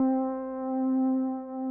Pad - Sky.wav